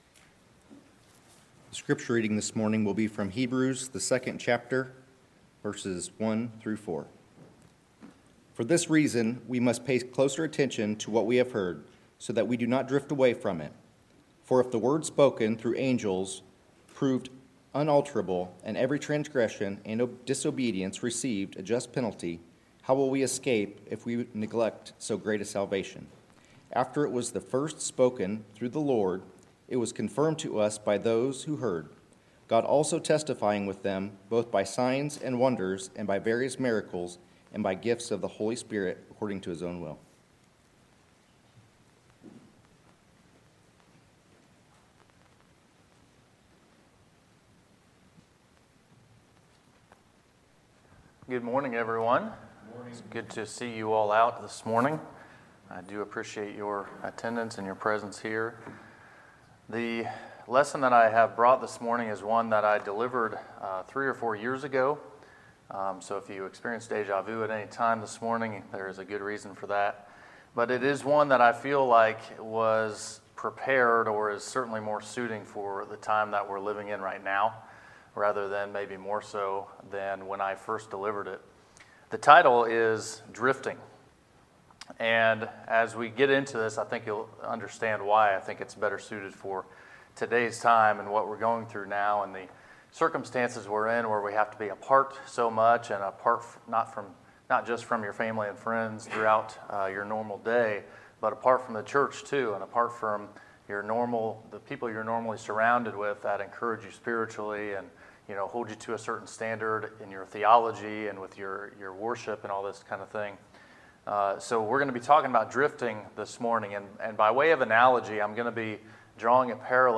Sermons, May 17, 2020